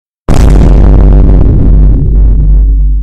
vine boom sound effect (bass boosted)
vine-boom-sound-effect-bass-boosted.mp3